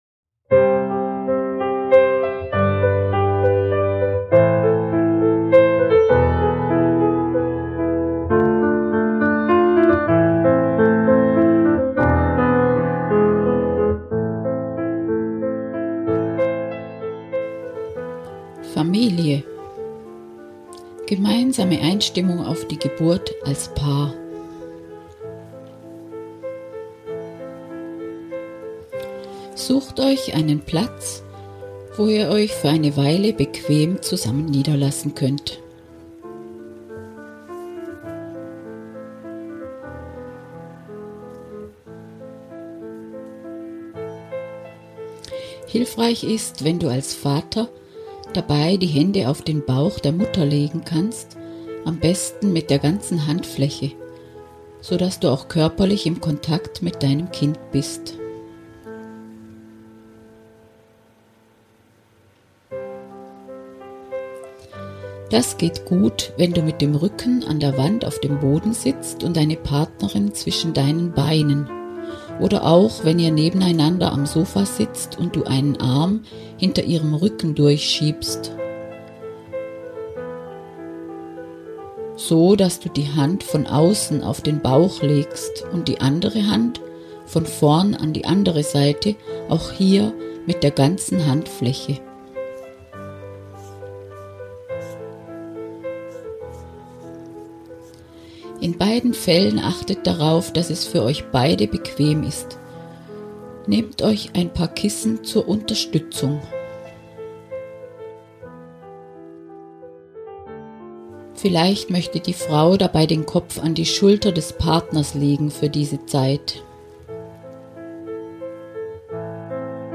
Die von mir besprochene und mit entspannender Klaviermusik hinterlegte CD hat zwei Sequenzen: Eine für Mutter und Kind und eine für euch als Paar mit dem Baby. Das gibt Euch die Möglichkeit, auch Zuhause mit Anleitung zu üben und so den Kontakt zwischen euch und zum Kind immer weiter zu vertiefen.